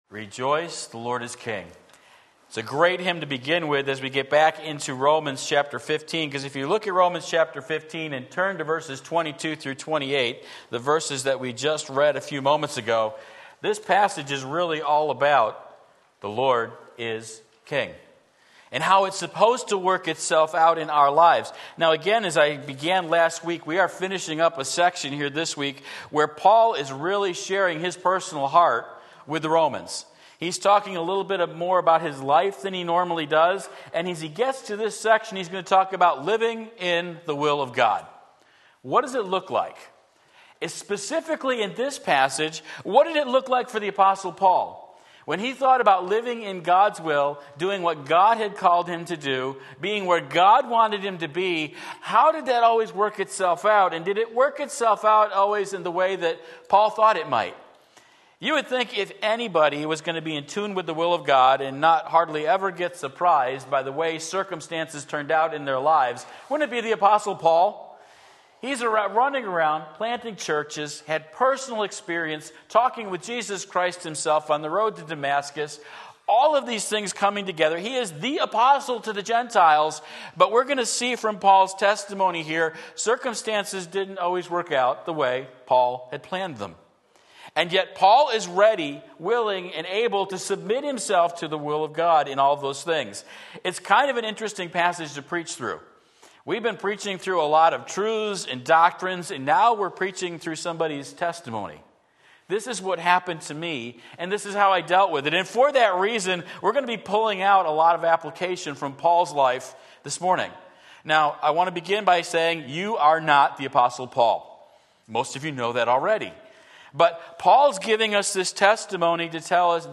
Sermon Link
Living in the Will of God Romans 15:22-28 Sunday Morning Service